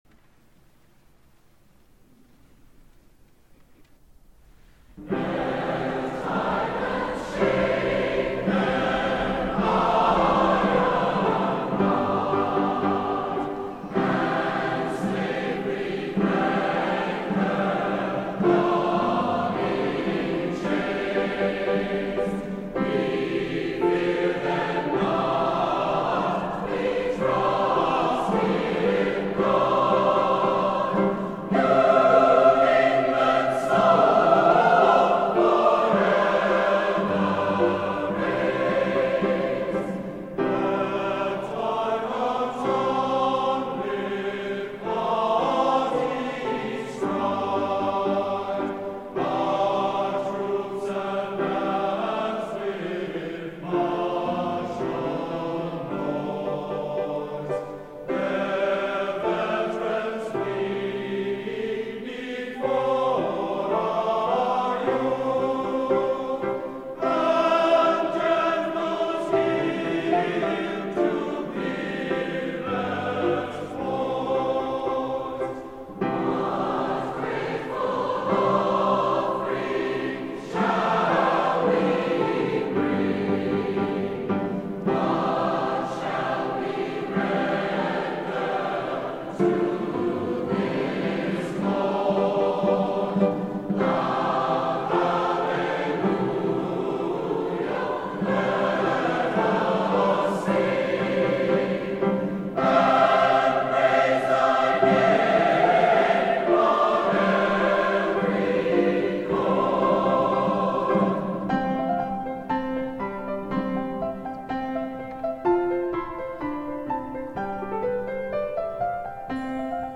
for SATB Chorus, Piano, and Opt. Percussion (2006)